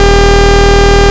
bzz.ogg